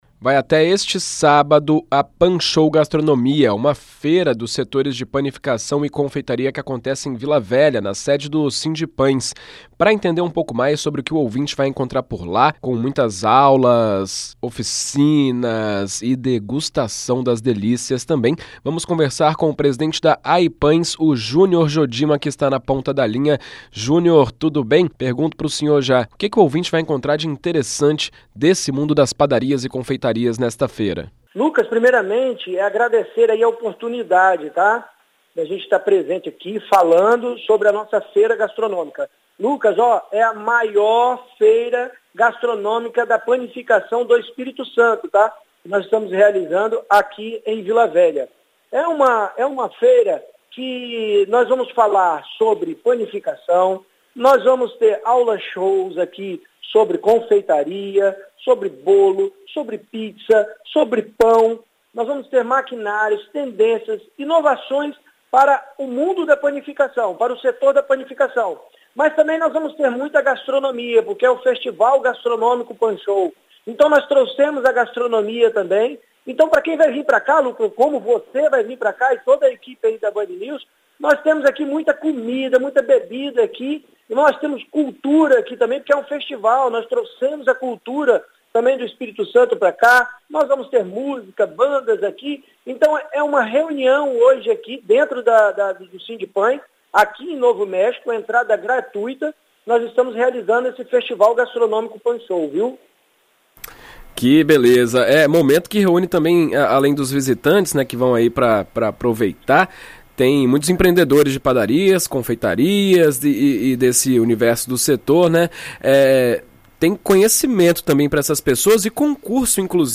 Receba as informações da BandNews ES no grupo da Rádio.